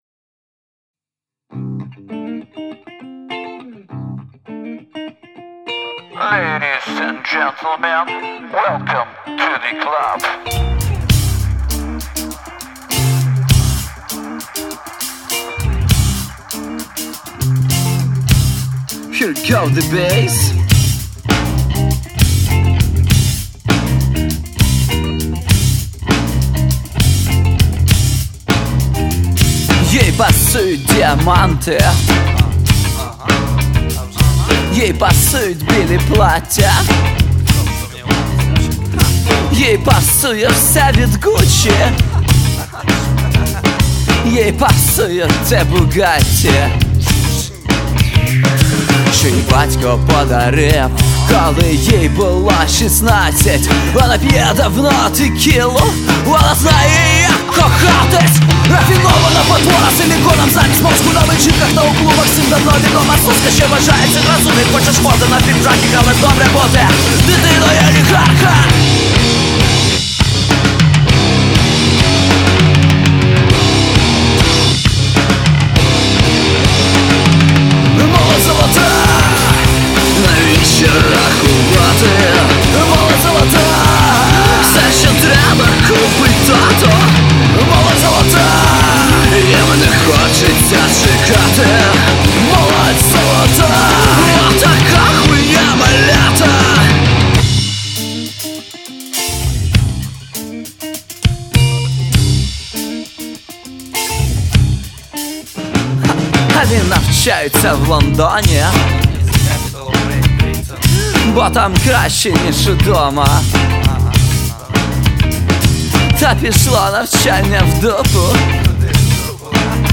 экспериментальную песню